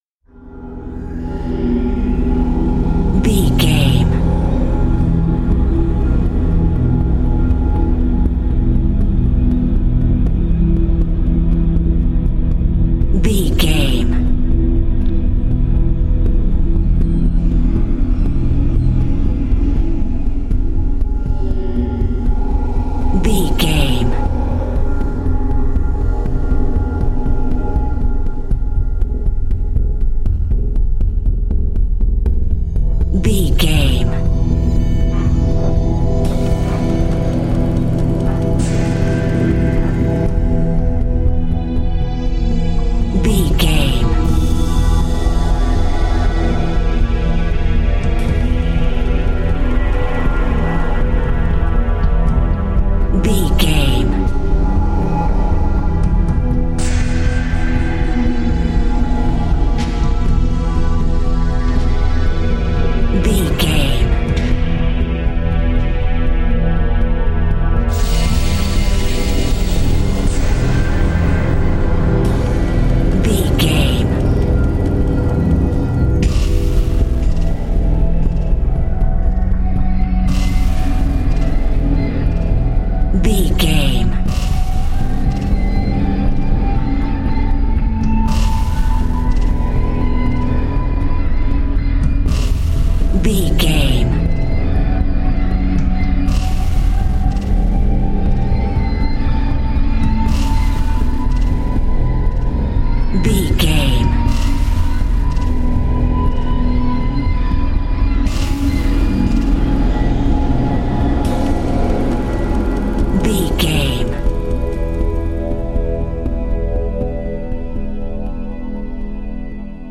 Aeolian/Minor
piano
synthesiser
percussion
tension
ominous
dark
suspense
haunting
creepy